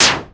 Hit8.wav